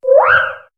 Cri de Chinchidou dans Pokémon HOME.